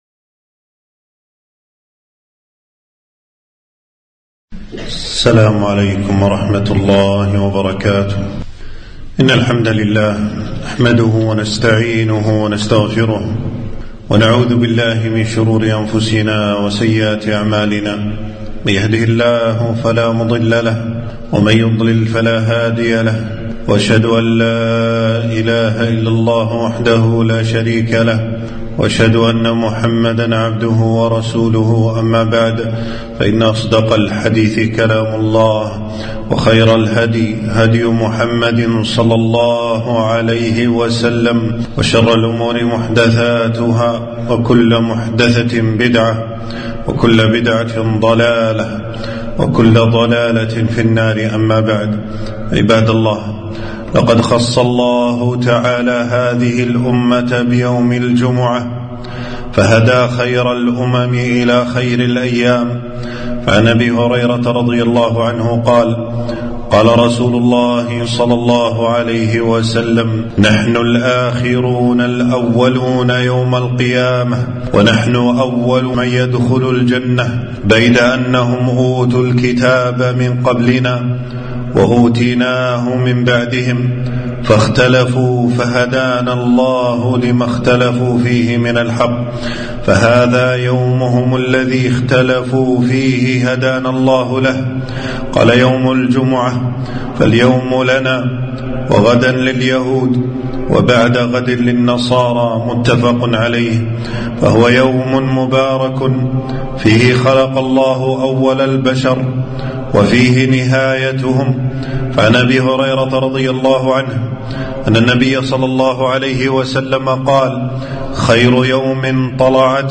خطبة - أحكام صلاة الجمعة وفضائلها